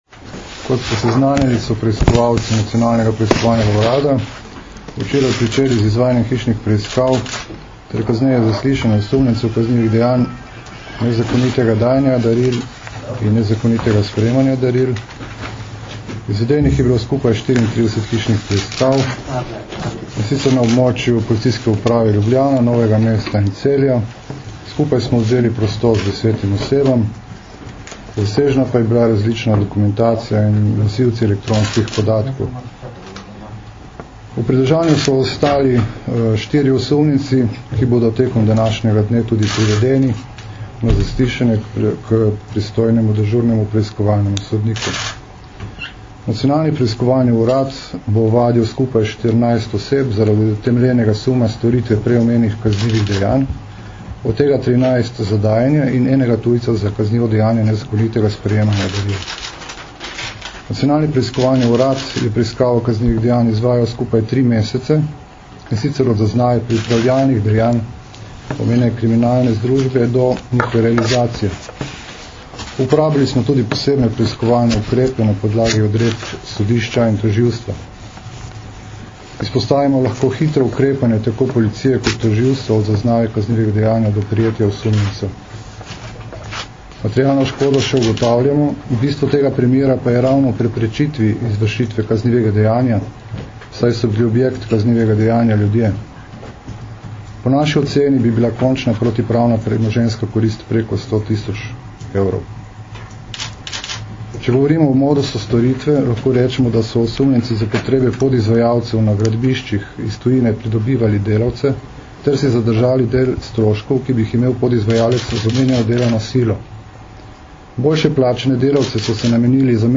Izjavi sta danes, 1. marca 2013, dala vodja Specializiranega državnega tožilstva RS mag. Harij Furlan (levo) in vodja Nacionalnega preiskovalnega urada mag. Bruno Blažina.
Zvočni posnetek izjave mag. Bruna Blažine (mp3)